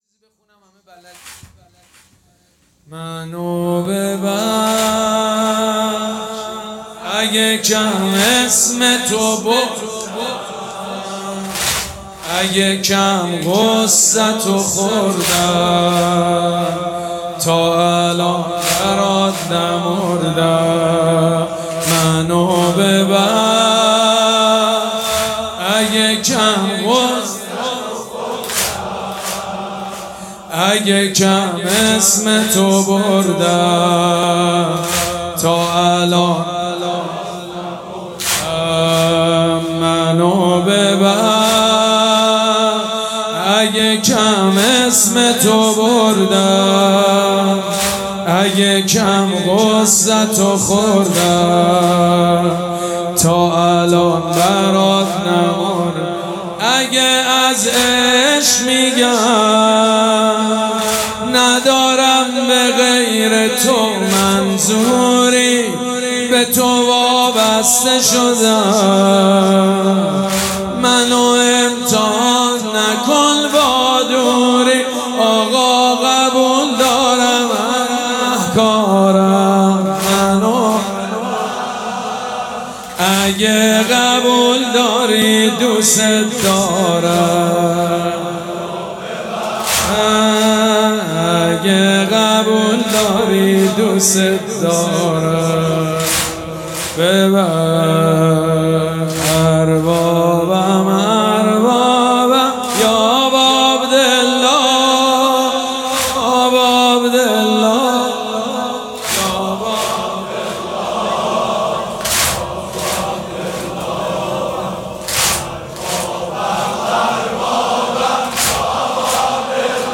مراسم عزاداری شب دهم محرم الحرام ۱۴۴۷
حاج سید مجید بنی فاطمه